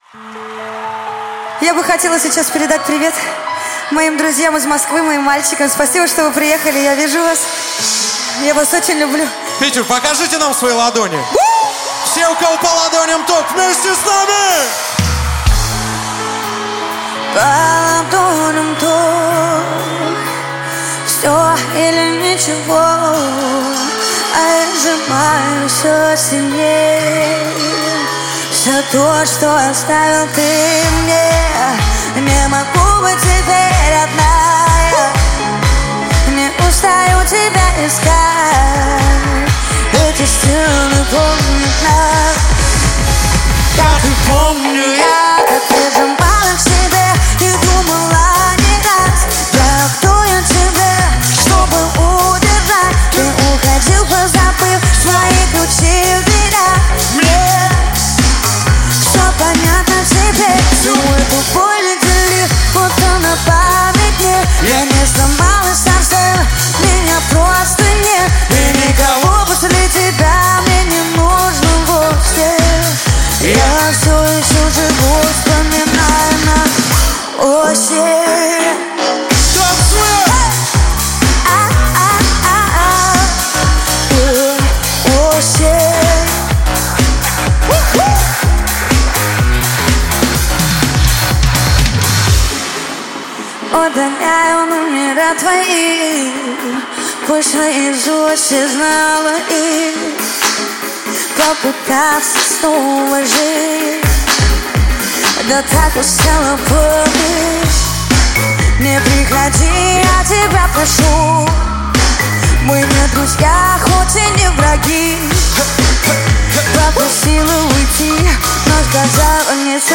Поп-музыка
Жанр: Жанры / Поп-музыка